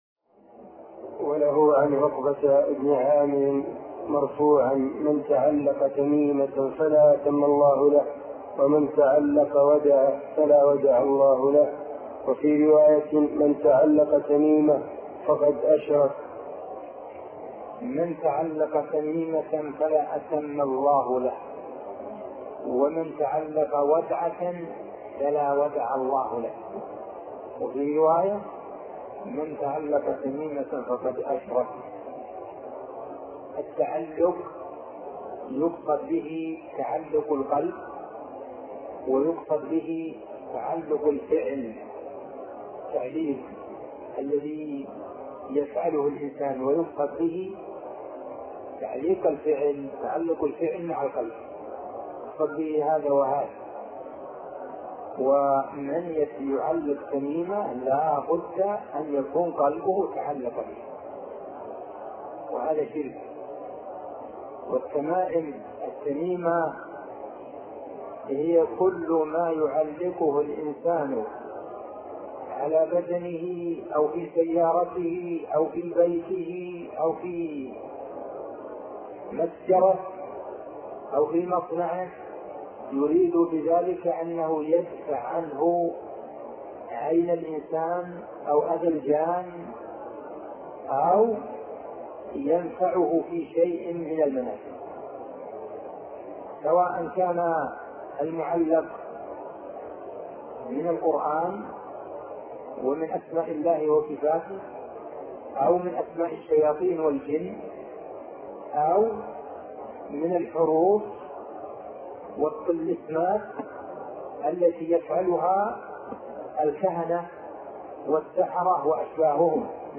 عنوان المادة الدرس ( 36) شرح فتح المجيد شرح كتاب التوحيد تاريخ التحميل الجمعة 16 ديسمبر 2022 مـ حجم المادة 18.51 ميجا بايت عدد الزيارات 201 زيارة عدد مرات الحفظ 119 مرة إستماع المادة حفظ المادة اضف تعليقك أرسل لصديق